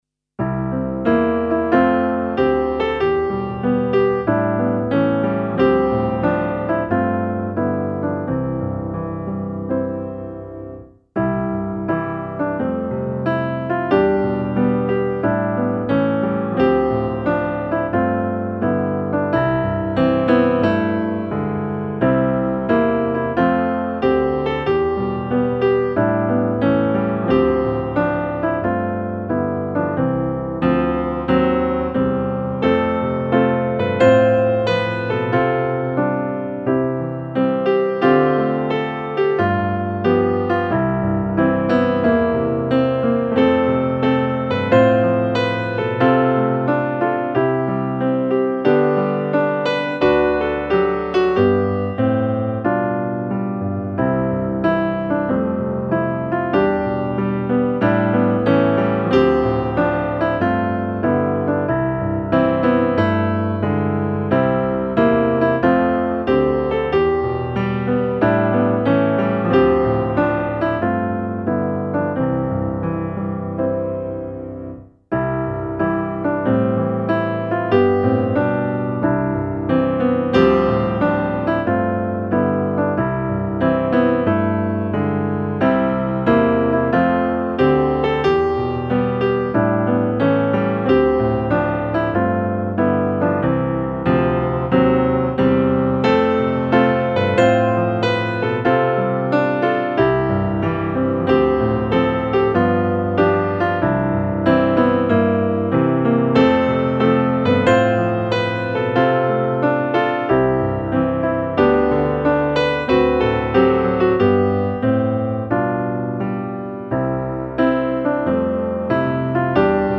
Låt mig få höra om Jesus - musikbakgrund